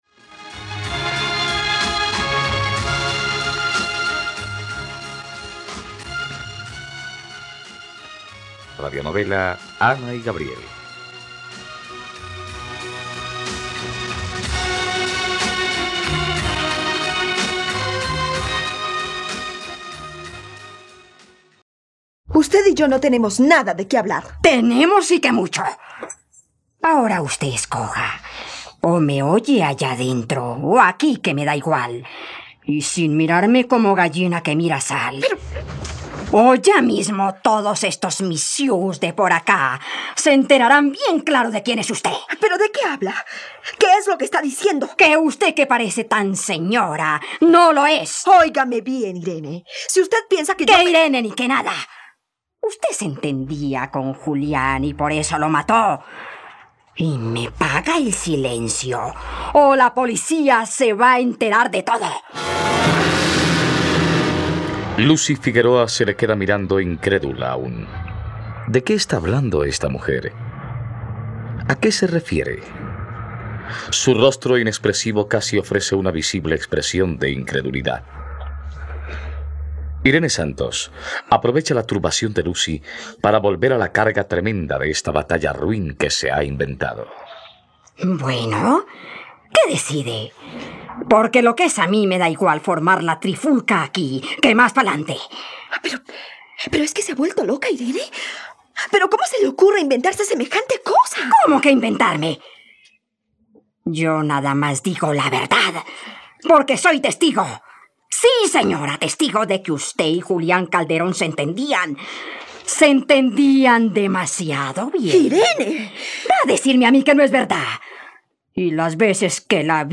..Radionovela. Escucha ahora el capítulo 100 de la historia de amor de Ana y Gabriel en la plataforma de streaming de los colombianos: RTVCPlay.